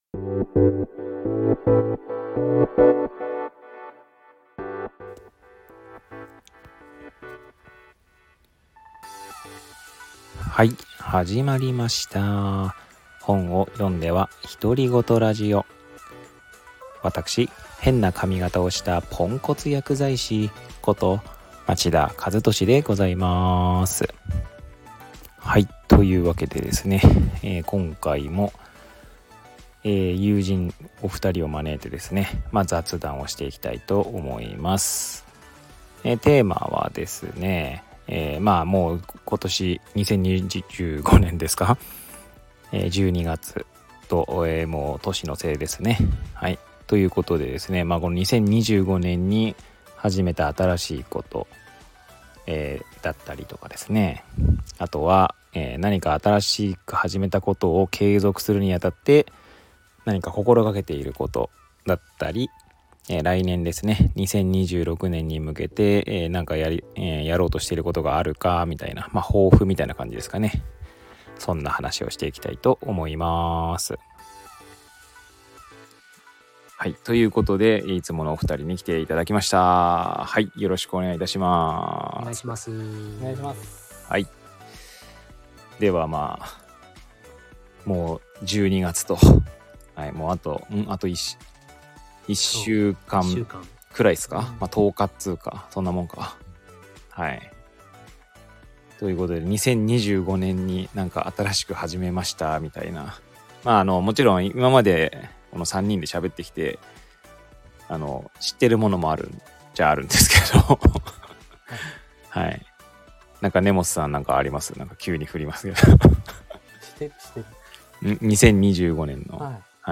友人との雑談会☆
毎月恒例の御三方とのコラボです〜